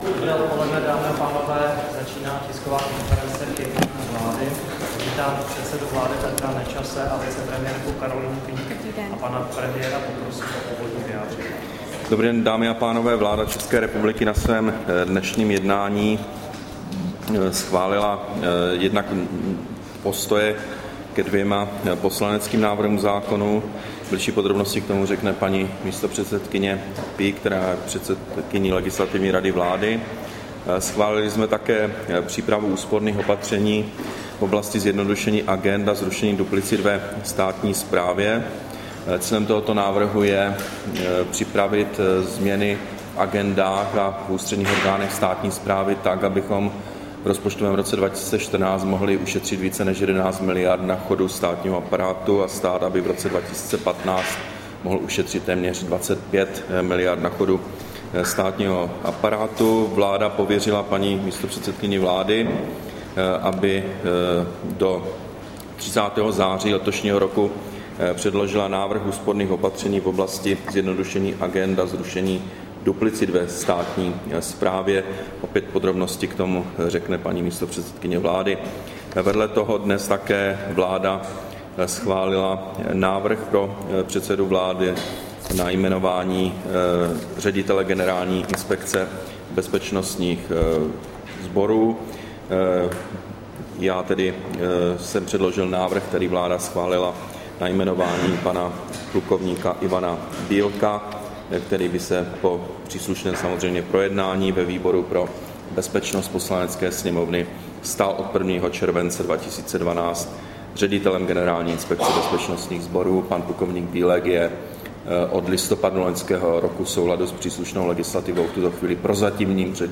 Tisková konference po jednání vlády, 6. června 2012